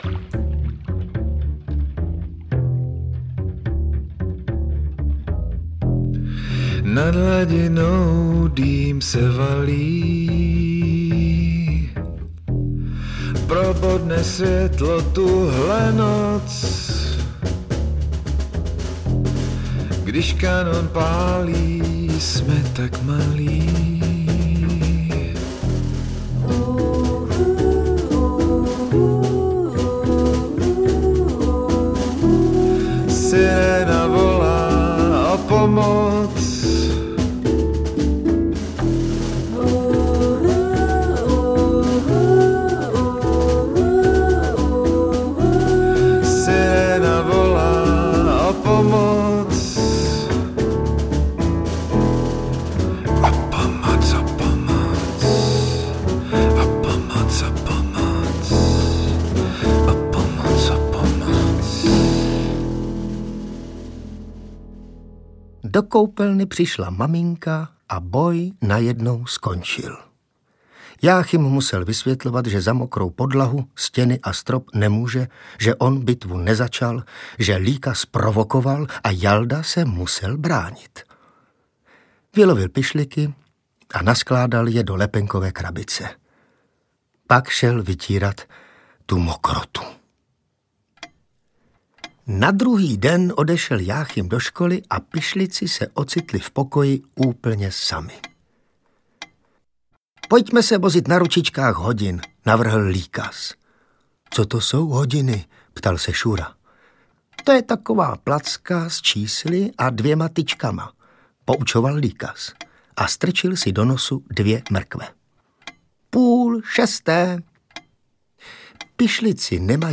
Ukázka z knihy
Filip Rychlebský napsal krásné pohádky o dřevěných figurkách - pišlicích - a na novém 2-CD je neodolatelně vypráví Ivan Trojan.